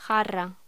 Locución: Jarra